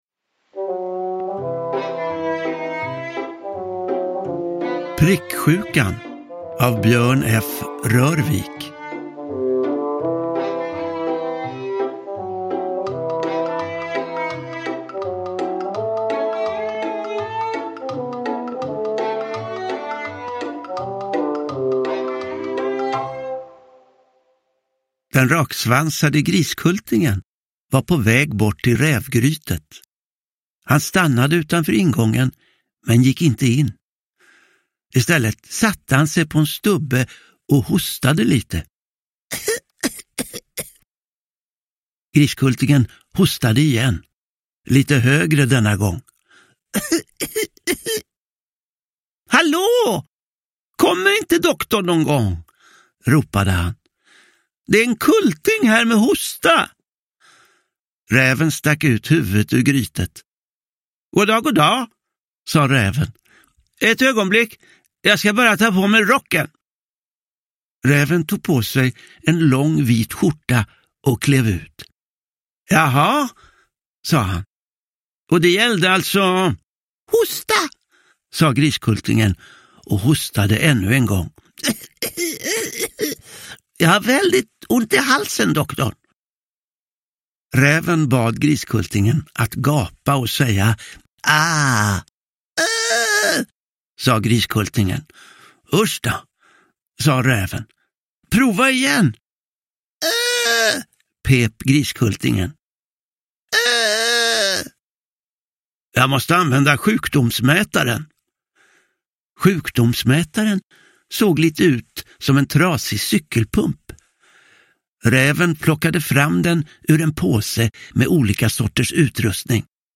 Produkttyp: Digitala böcker
Uppläsare: Johan Ulveson